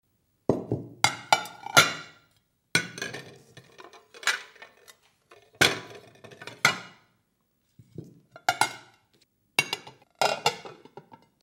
PLATOS EN LA MESA
Ambient sound effects
Platos_en_la_mesa.mp3